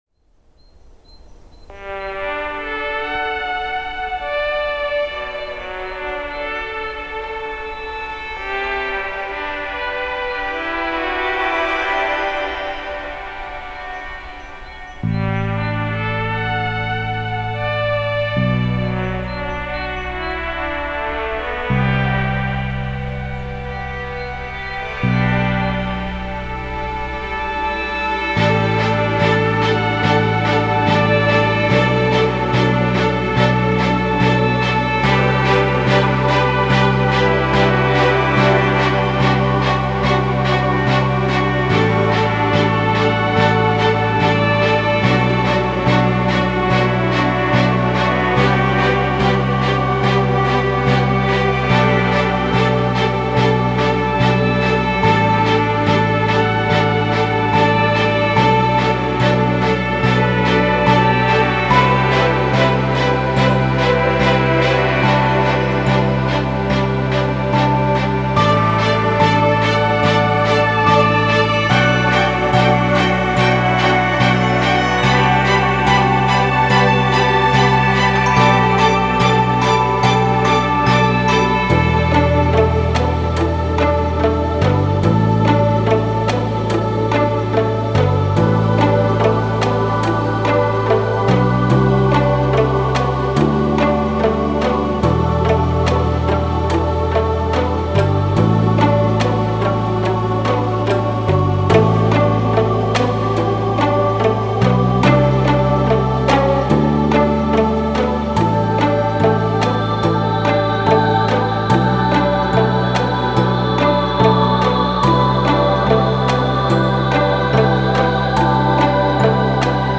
Such a cute remix!
Cinematic
• LABS Soft Piano